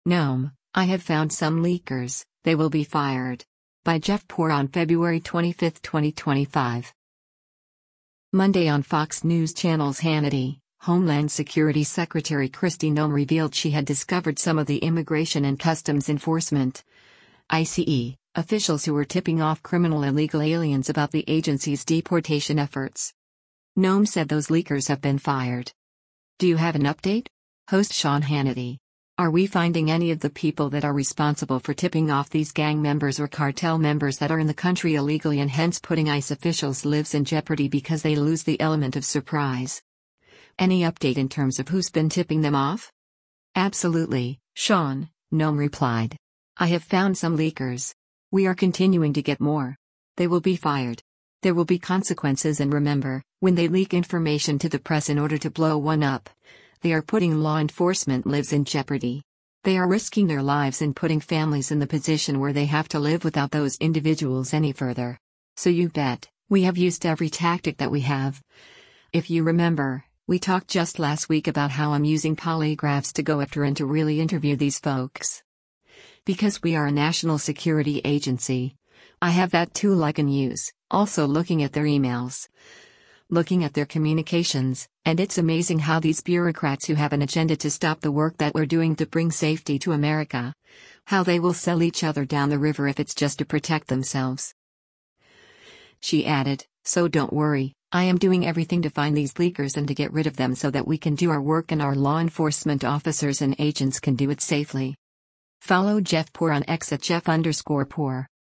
Monday on Fox News Channel’s “Hannity,” Homeland Security Secretary Kristi Noem revealed she had discovered some of the Immigration and Customs Enforcement (ICE) officials who were tipping off criminal illegal aliens about the agency’s deportation efforts.